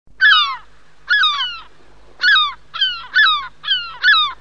La mouette | Université populaire de la biosphère
Elle glappit et jappe
mouette.mp3